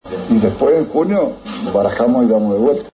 ARCHIVO: Gildo Insfrán , marzo 2020, al anunciar el aumento del 15% para empleados públicos.